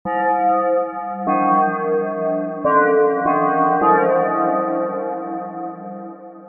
• Качество: 320, Stereo
спокойные
без слов
инструментальные
звук колокола
бой колокола